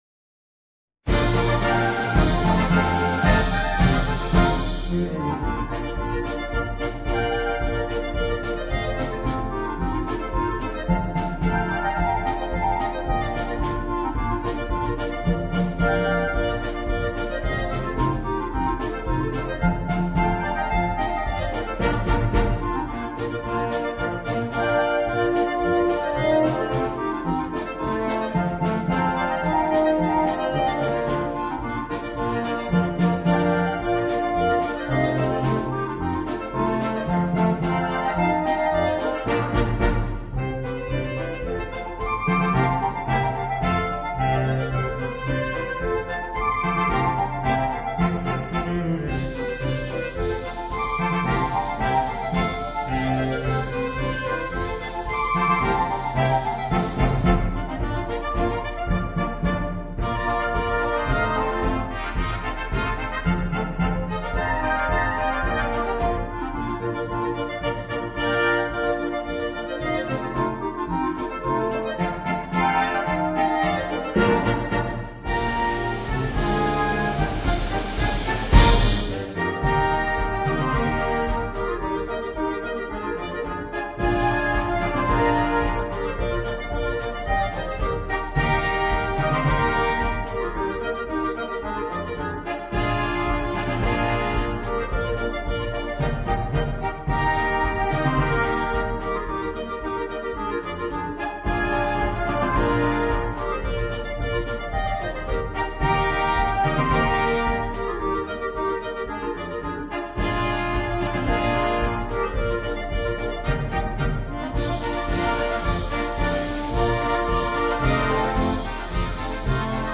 La celebre e divertente Polka per due clarinetti e banda.